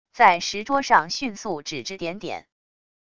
在石桌上迅速指指点点wav音频